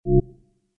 28_rollOverBtnSound.mp3